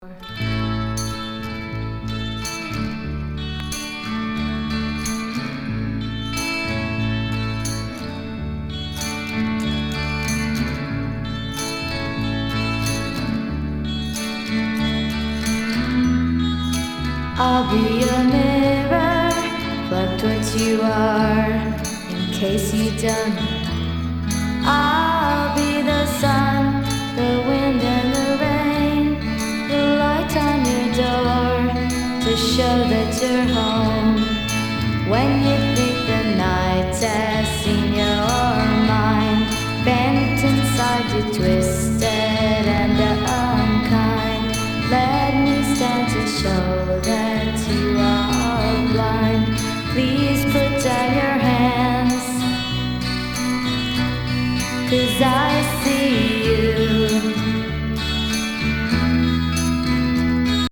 60’ｓ～70’sのフォークロックやサイケ